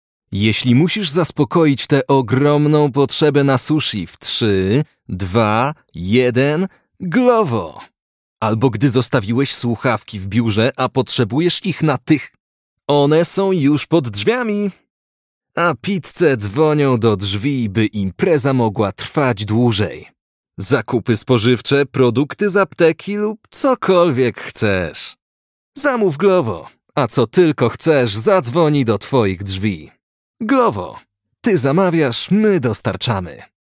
Mężczyzna 30-50 lat
Lektor brzmiący wyśmienicie w średnich rejestrach, pracujący we własnym studio, dzięki czemu Klient może liczyć na szybką realizację zleceń.
Nagranie do spotu reklamowego